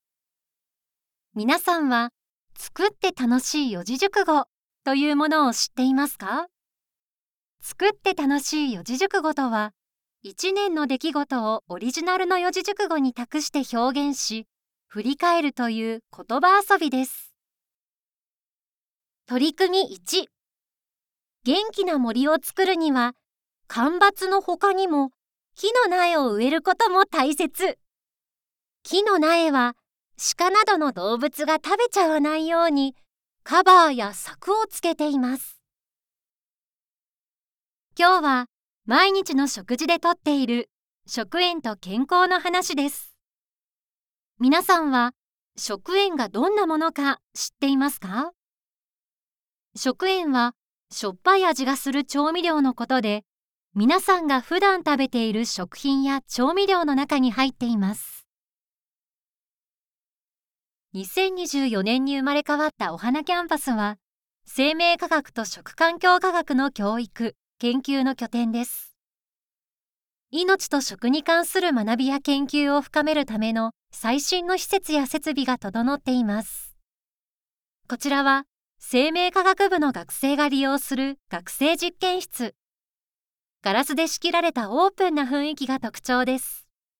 • 【教育】 ①高学年向け　②低学年向け　③中学生向け　④高校生向け